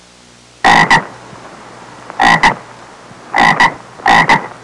Bullfrog Sound Effect
Download a high-quality bullfrog sound effect.
bullfrog.mp3